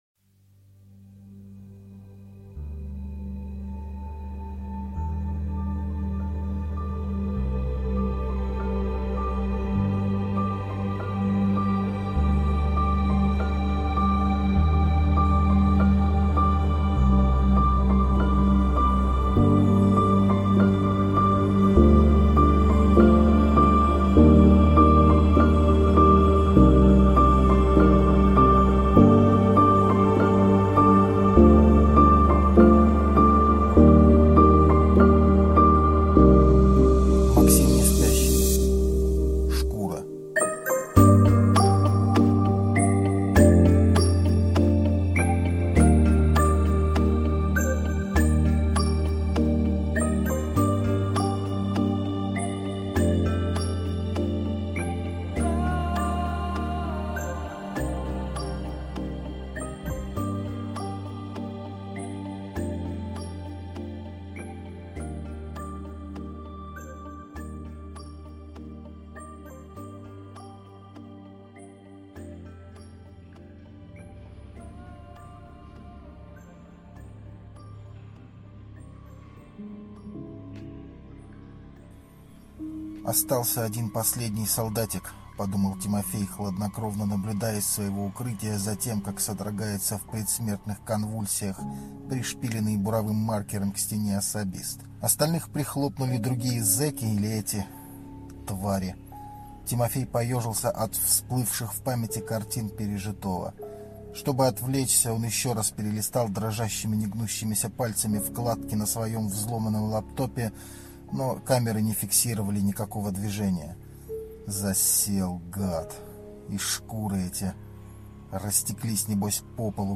Аудиокнига Шкура | Библиотека аудиокниг